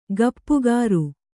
♪ gappu gāru